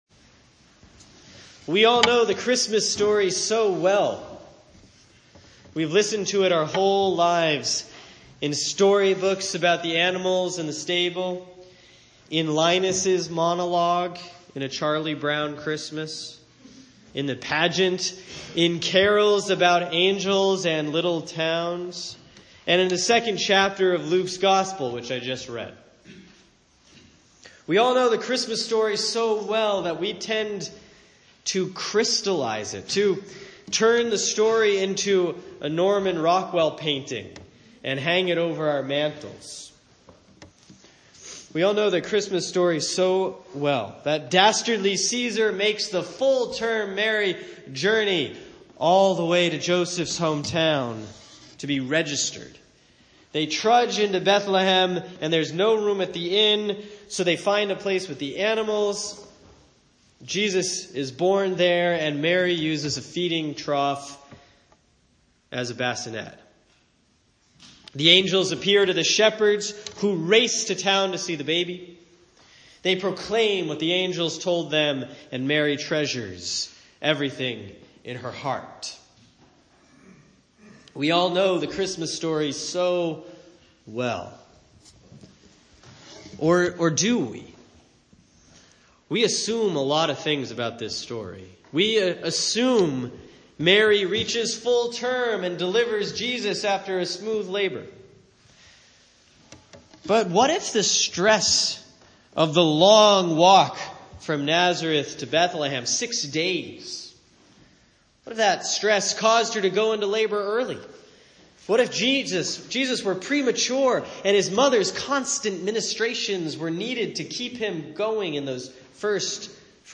Sermon for Christmas Eve 2016 || Luke 2:1-20